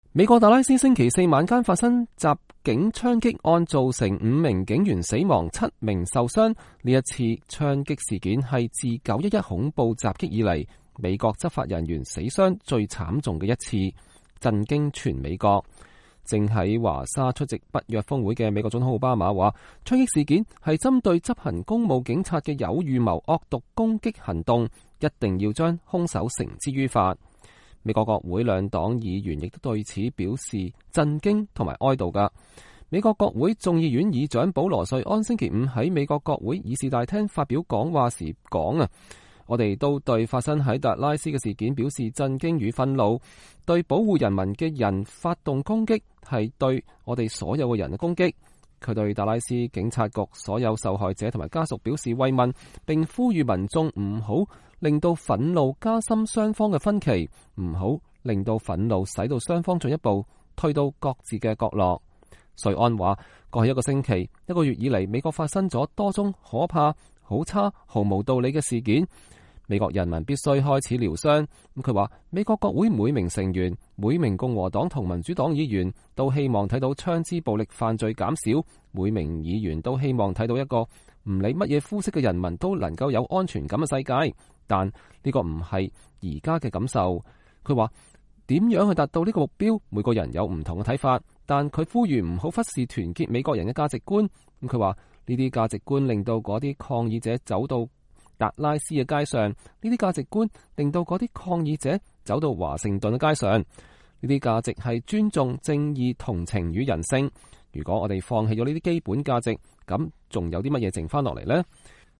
保羅瑞安在議事大廳就達拉斯槍擊事件發表講話